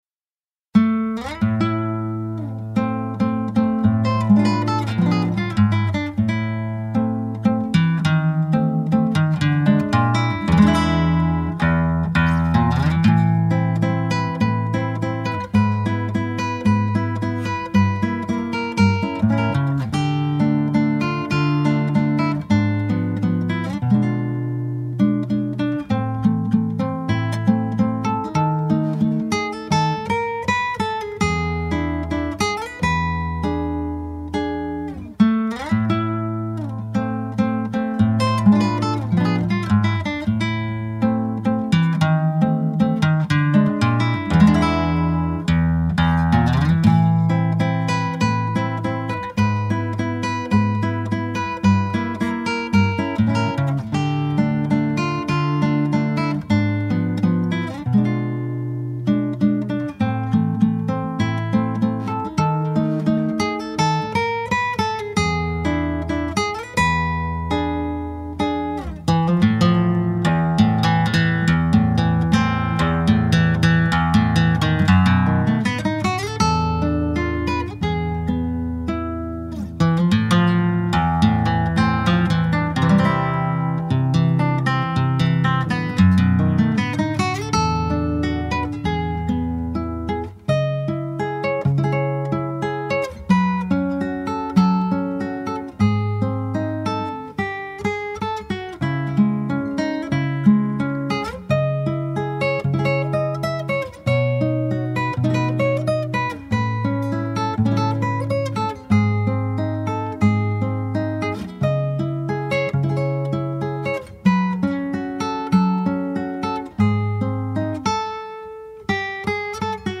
2650   02:22:00   Faixa: 7    Mpb
Violao Acústico 6